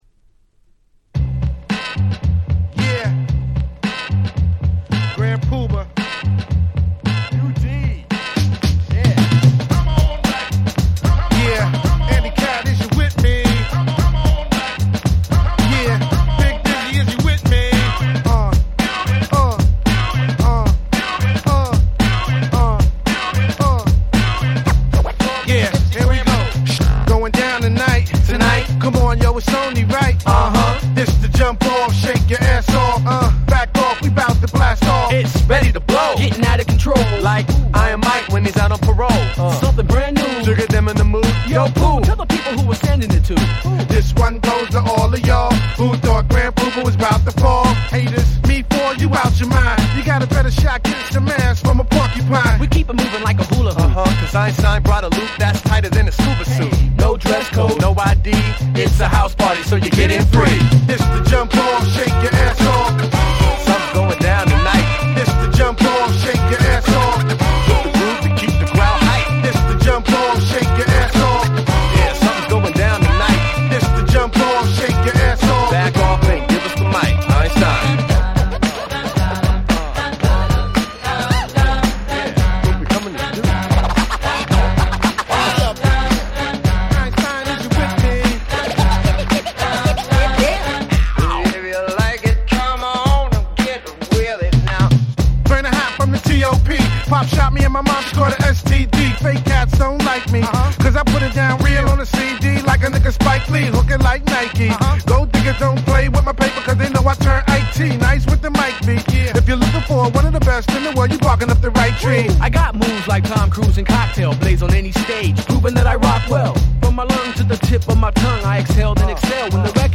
Boom Bap